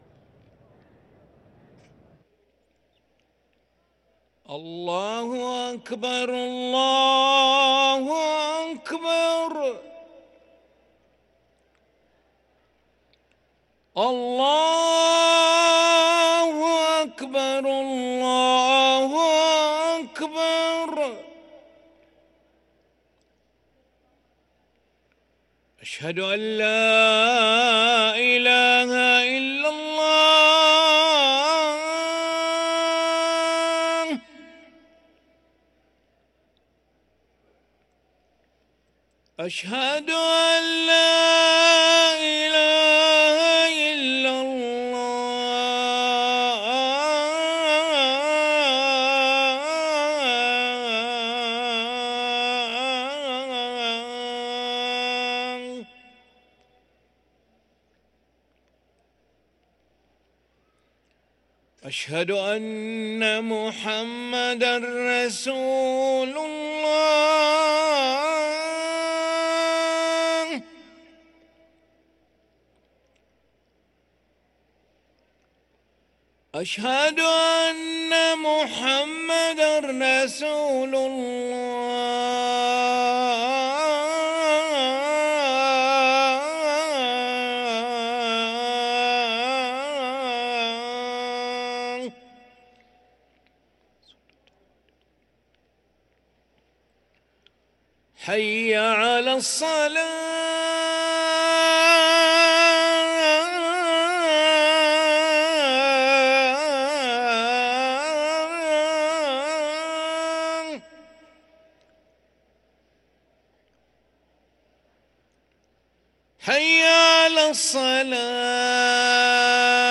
أذان العشاء للمؤذن علي ملا الخميس 10 شعبان 1444هـ > ١٤٤٤ 🕋 > ركن الأذان 🕋 > المزيد - تلاوات الحرمين